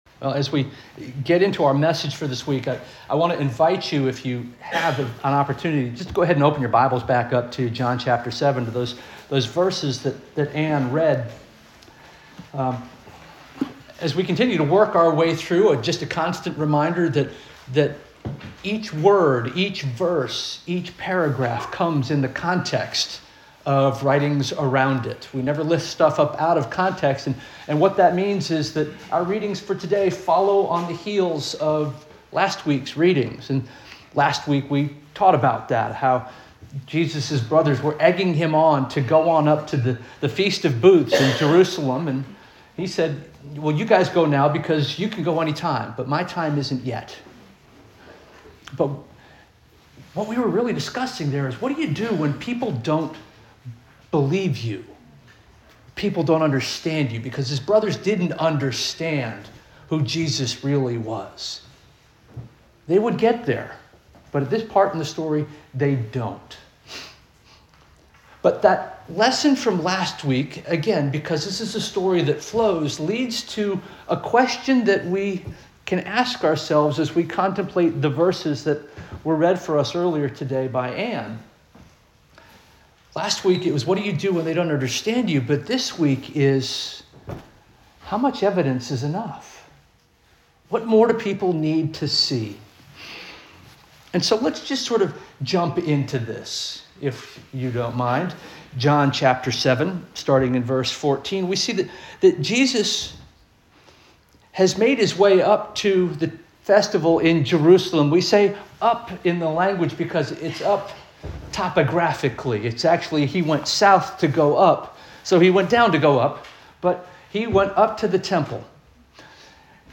February 15 2026 Sermon - First Union African Baptist Church